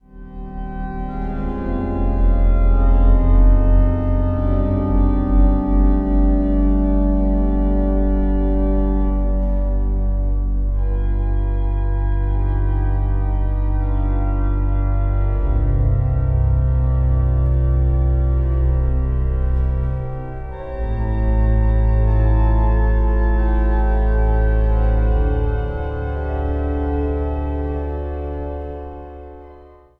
Improvisatie op zendingspsalmen.